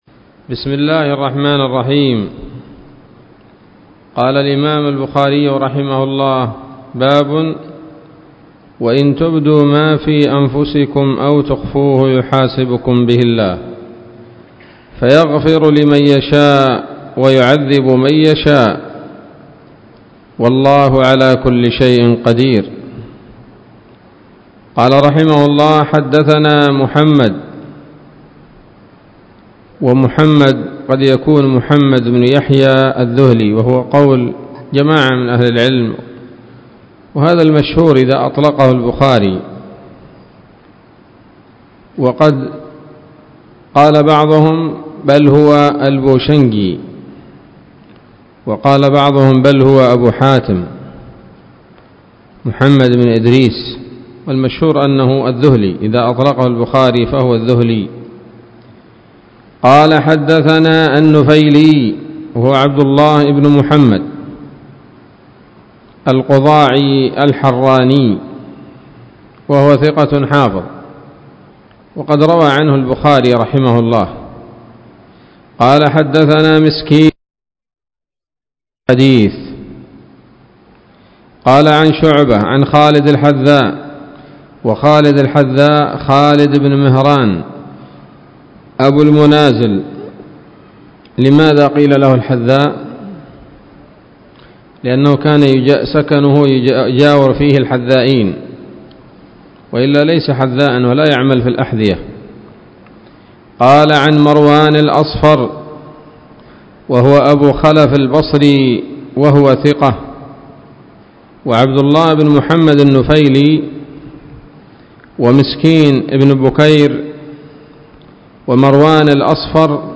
الدرس الرابع والأربعون من كتاب التفسير من صحيح الإمام البخاري